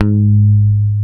-JP PICK G#3.wav